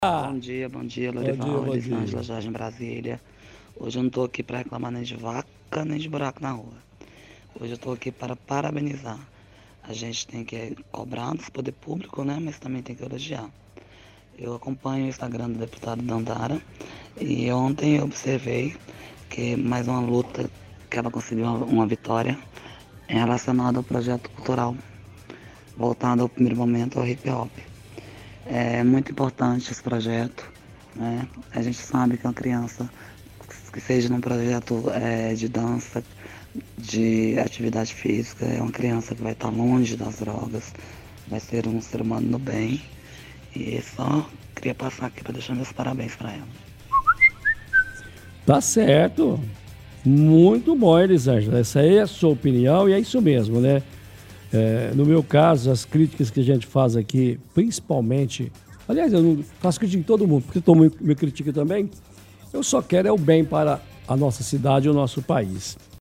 – Ouvinte congratula a deputada federal Dandara por trazer o projeto cultural envolvendo o Hip-Hop.